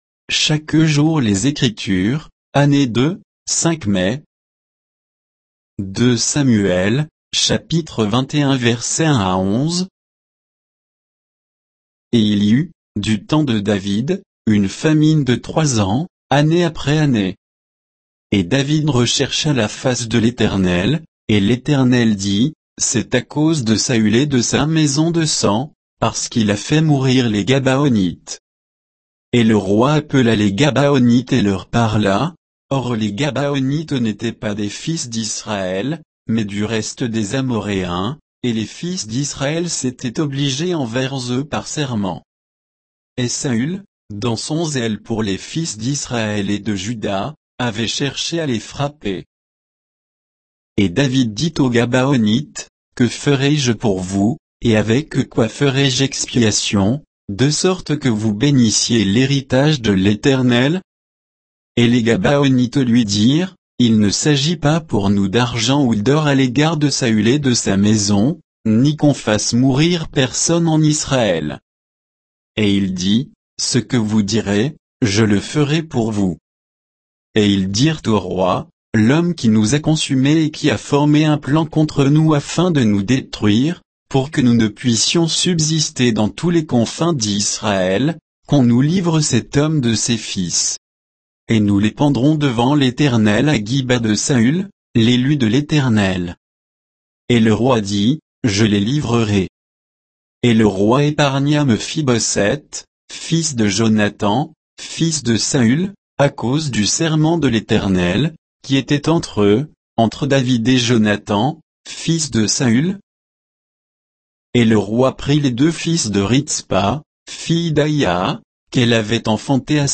Méditation quoditienne de Chaque jour les Écritures sur 2 Samuel 21, 1 à 11